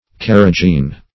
Carrigeen \Car"ri*geen`\